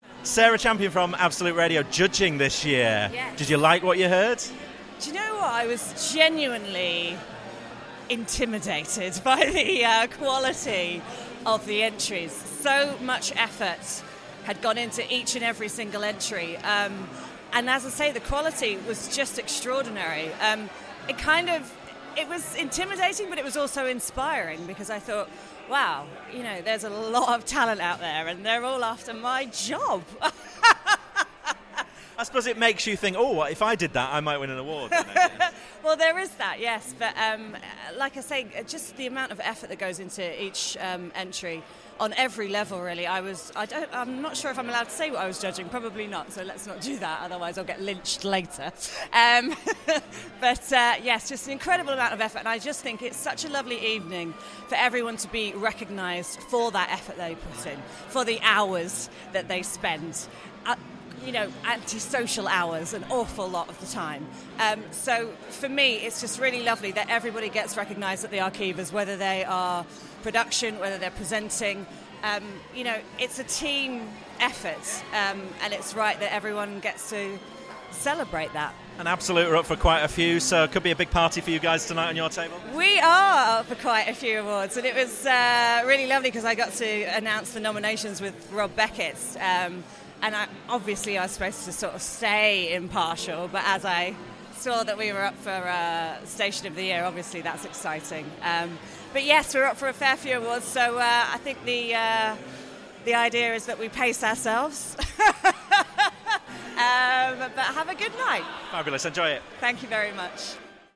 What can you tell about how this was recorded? at the Arqiva Commercial Radio Awards 2016.